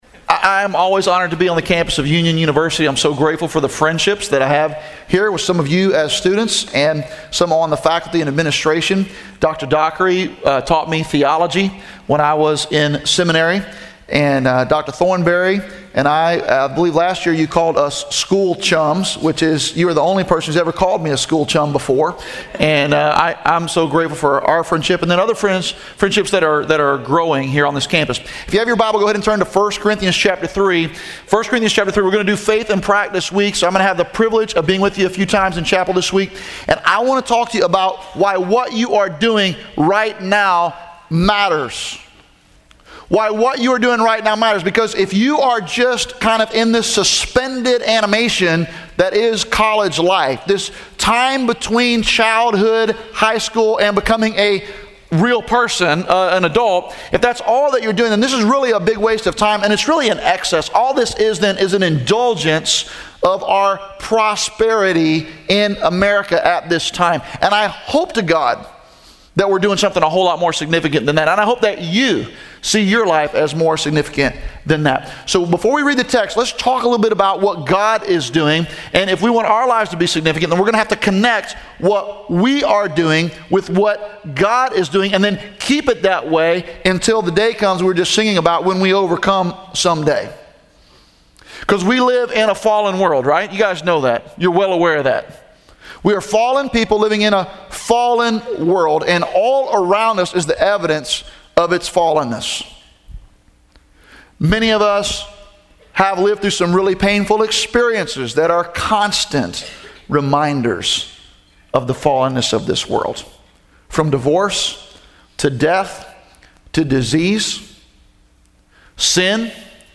Address: Session 1